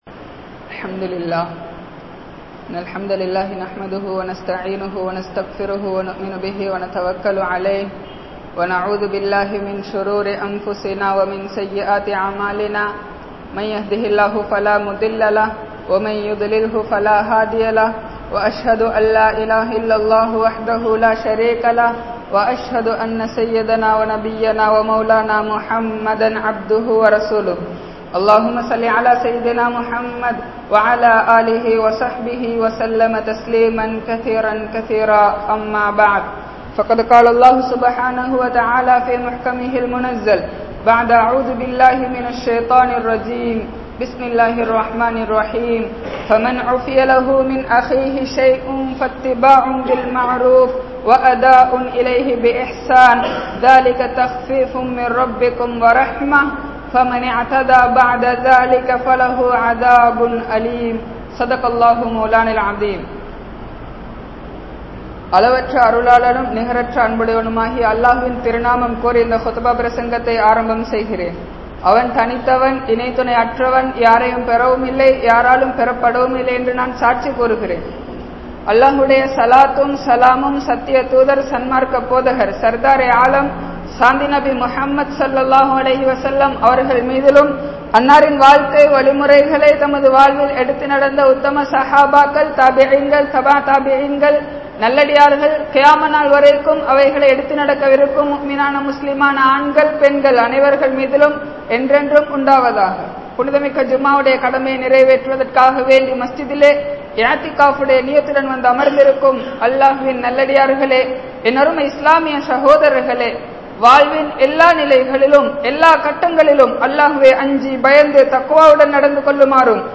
Mattravarhalai Manniungal (மற்றவர்களை மன்னியுங்கள்) | Audio Bayans | All Ceylon Muslim Youth Community | Addalaichenai
Gorakana Jumuah Masjith